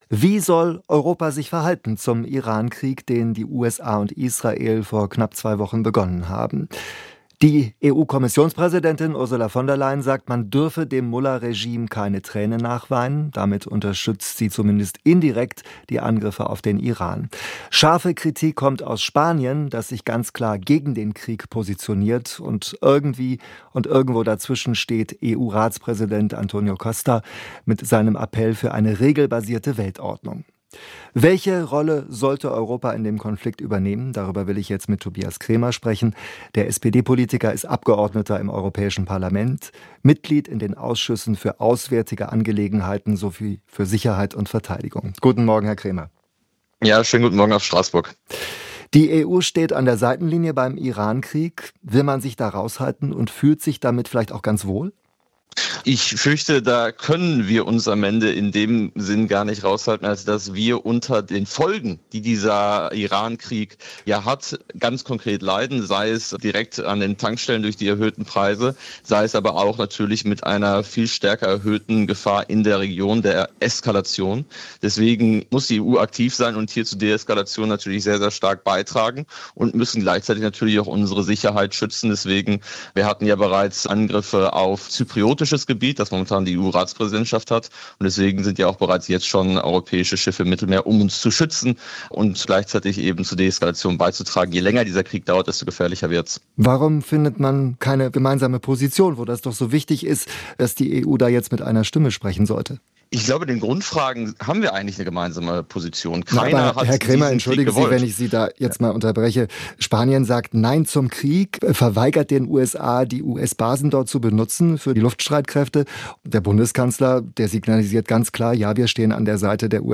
Wie das gelingen kann, erklärt Außenpolitiker Cremer im Gespräch mit SWR Aktuell.